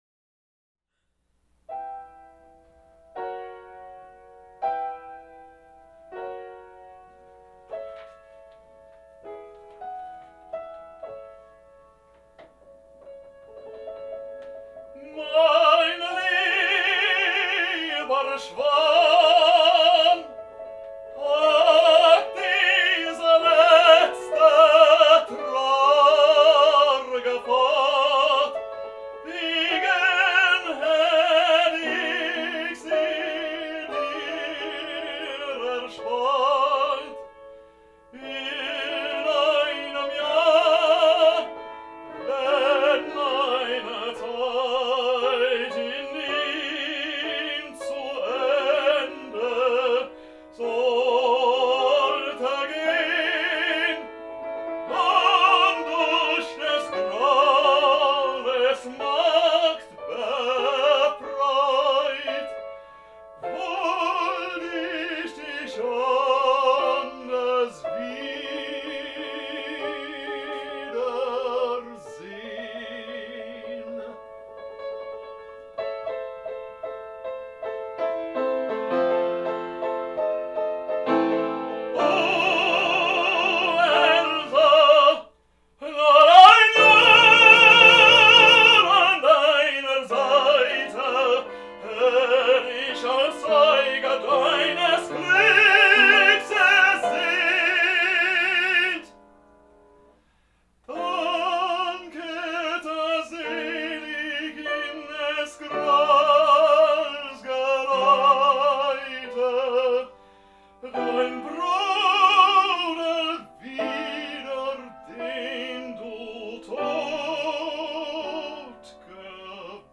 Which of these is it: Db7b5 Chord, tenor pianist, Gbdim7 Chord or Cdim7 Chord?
tenor pianist